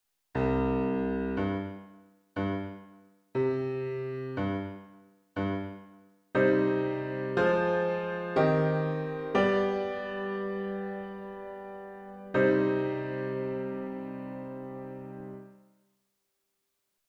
5.7 Dictations